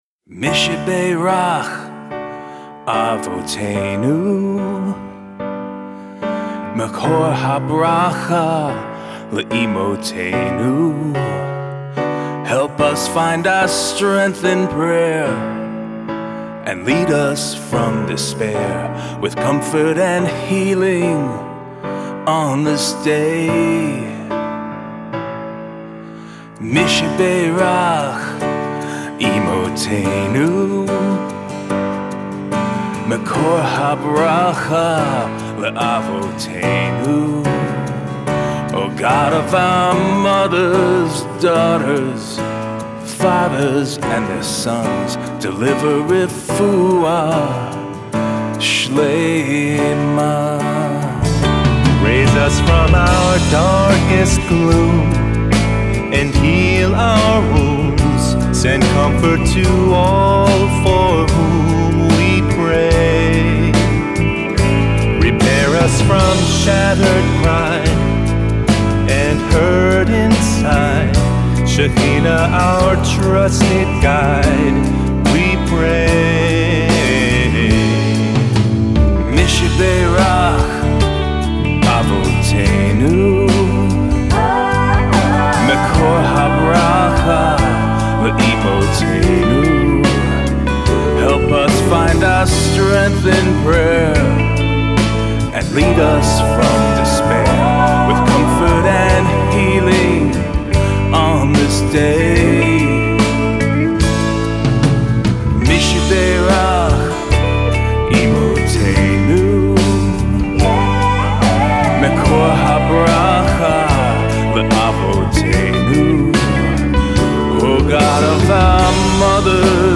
contemporary Shabbat music